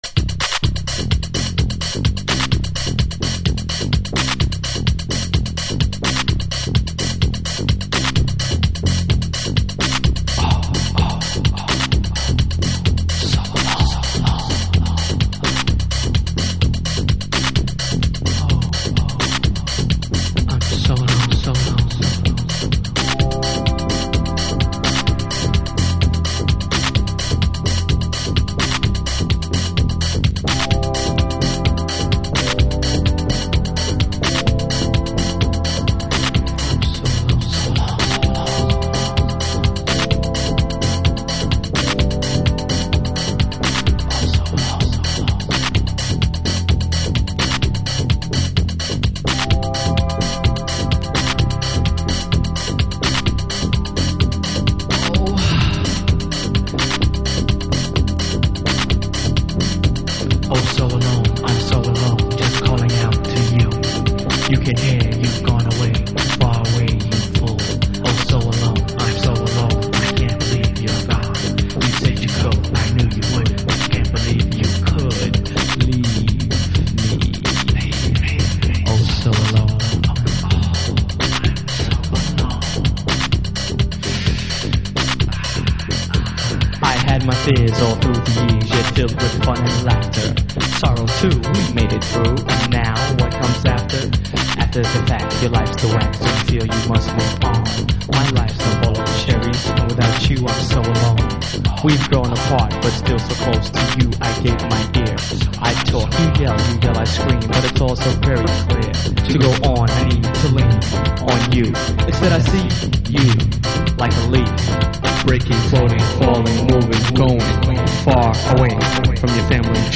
まるでマイクオールドフィールドやジャンミッシェルジャールを彷彿させるトラックは当時のハウスシーンでも異形だったハズ。